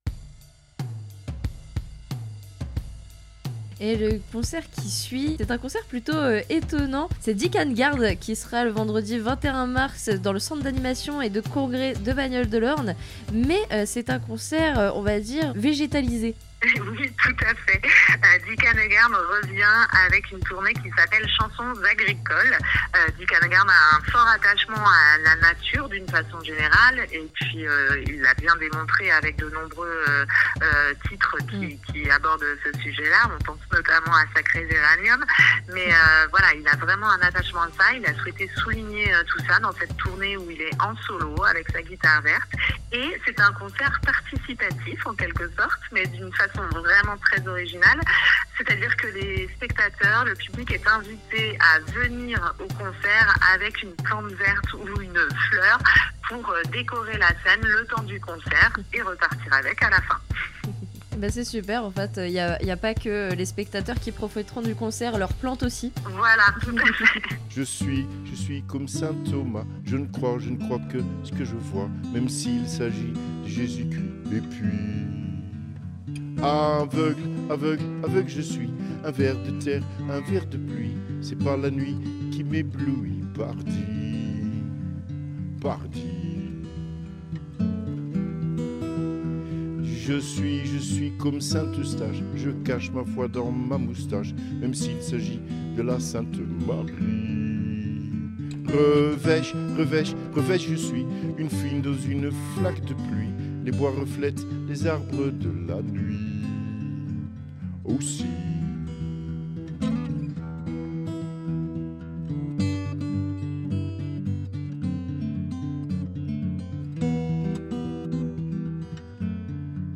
Interviews